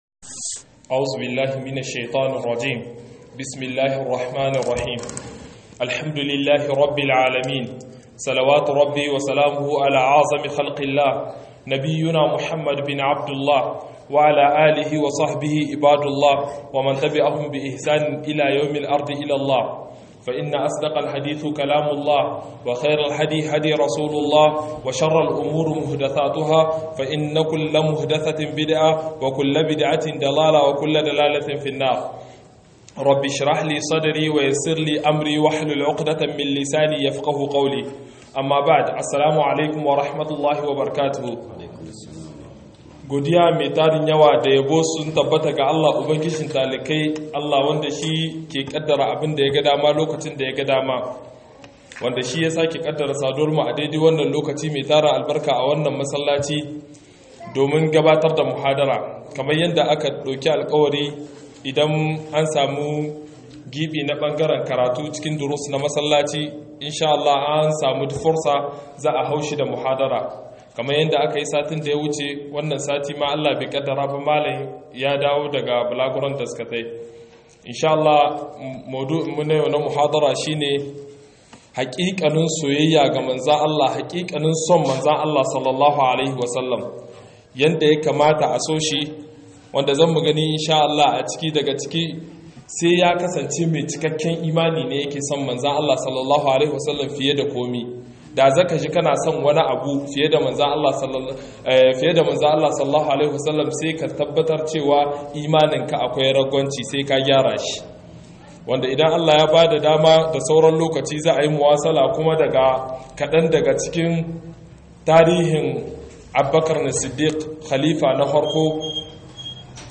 HAKIKANIN_SOYAYAR_ANNABI_S_A_W_2022_02_08_18'46'04' - MUHADARA